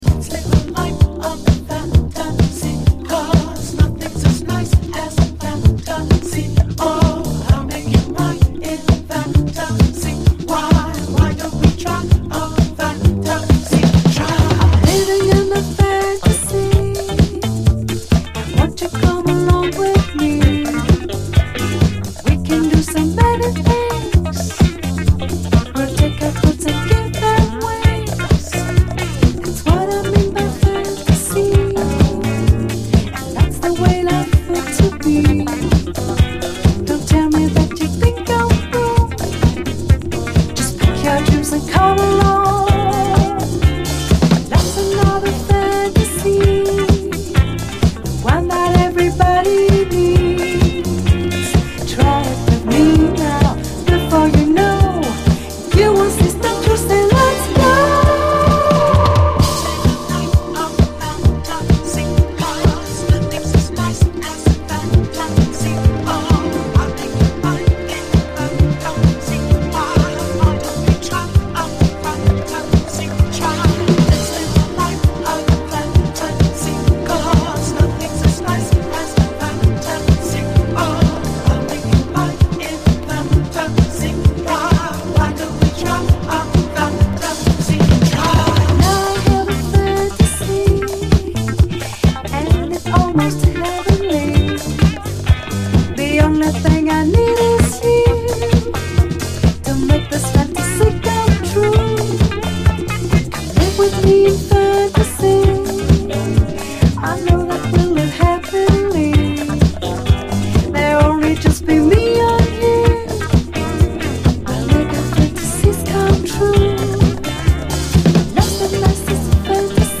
SOUL, 70's～ SOUL, DISCO, 7INCH
隠れたフランス産ミディアム・レディー・ディスコ・ダンサー！